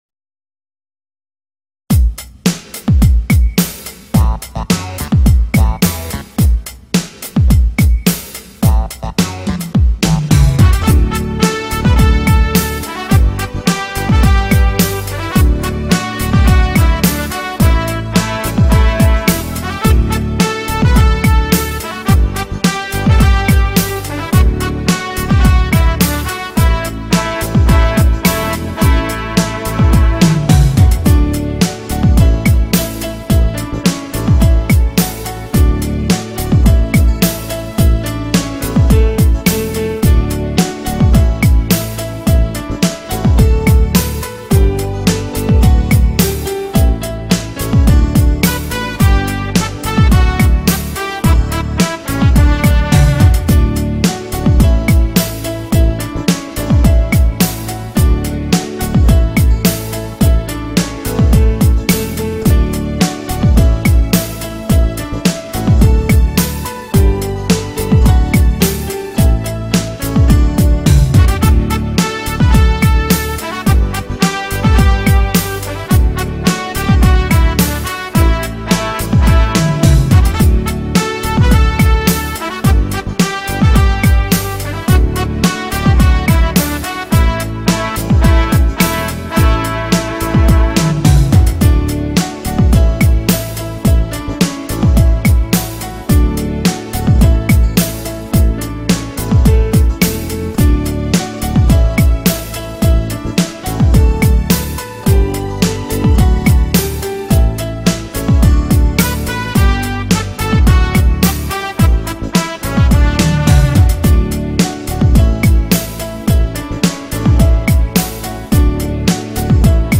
Nhạc beat: Lớp chúng ta đoàn kết.